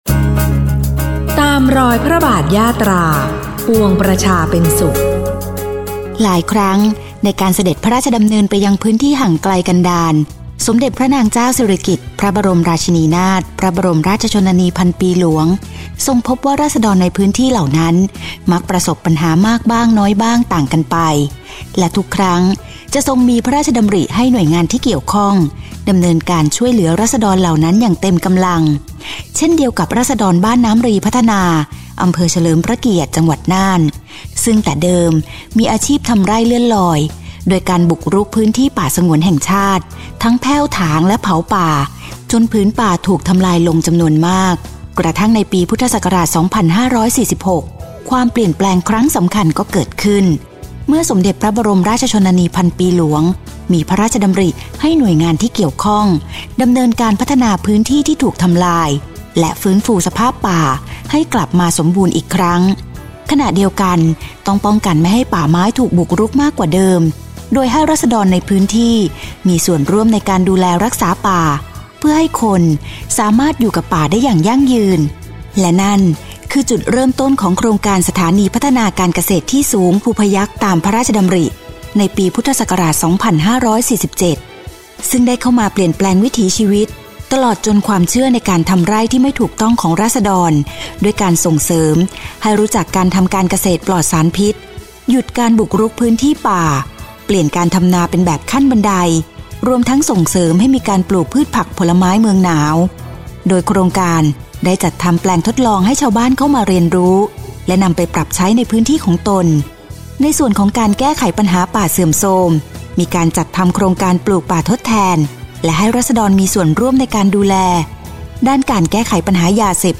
ปี 2568 : ข่าวประชาสัมพันธ์ ตอนที่ 4 สถานีพัฒนาการเกษตรที่สูงภูพยัคฆ์ ฯ